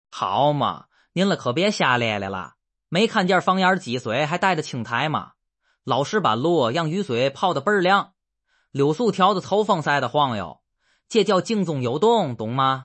描述：天津相声，专业捧哏。
支持的语种/方言：中文（天津话）